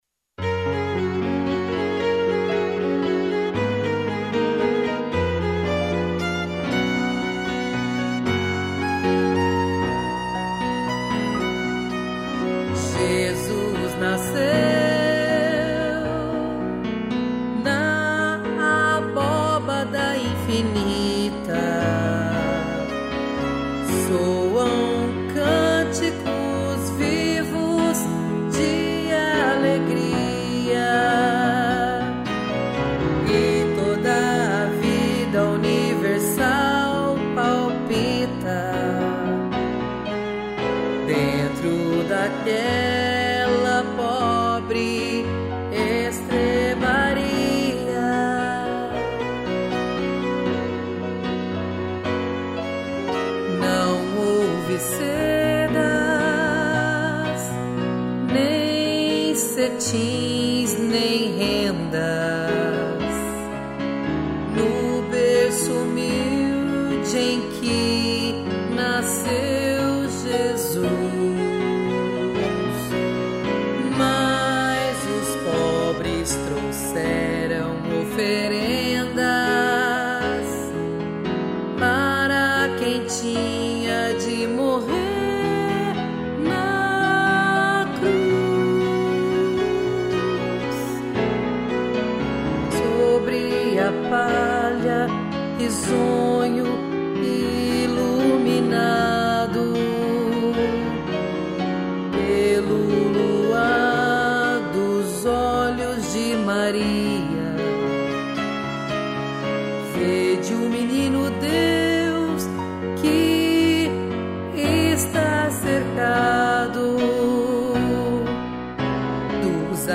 2 pianos, violino e cello